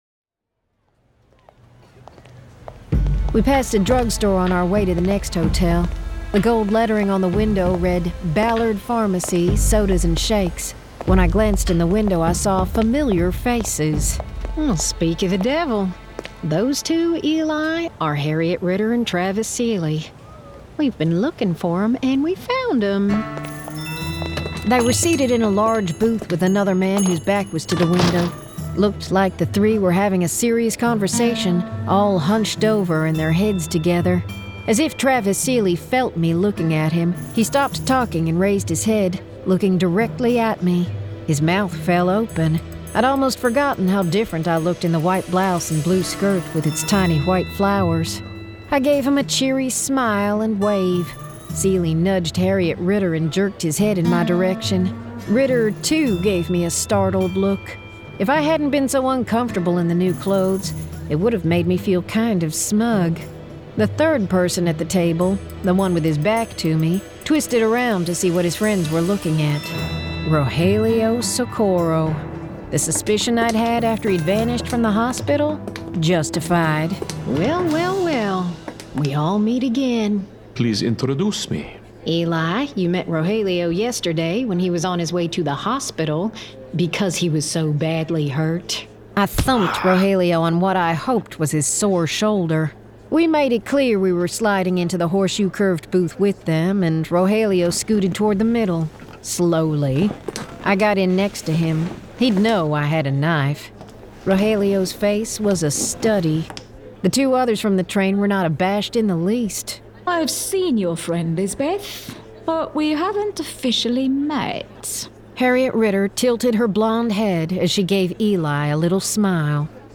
Full Cast. Cinematic Music. Sound Effects.
[Dramatized Adaptation]
Genre: Western